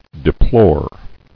[de·plore]